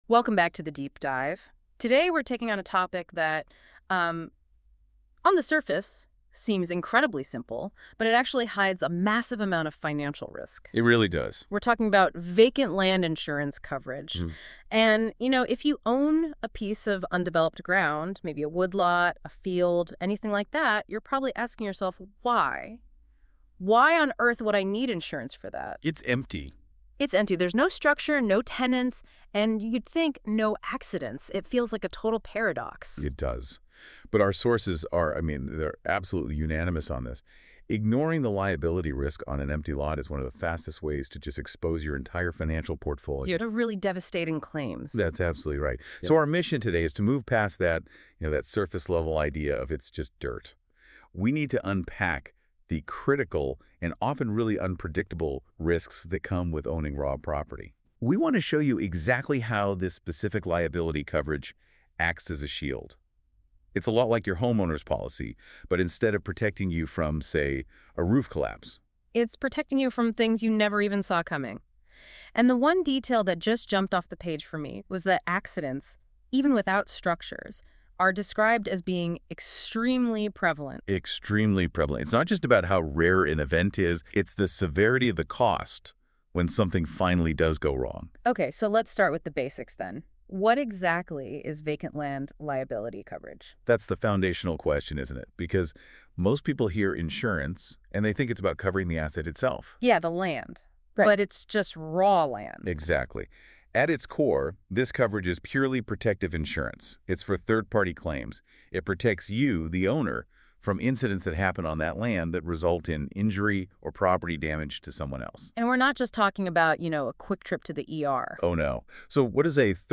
This protective coverage does not insure the dirt itself but rather shields the owner’s personal assets from third-party claims related to injury or property damage that occur on the empty parcel. Speakers outline several high-risk scenarios, including liability arising from both permitted use (such as allowing a neighbor to fish) and unauthorized trespass by the public, especially where dangerous conditions exist.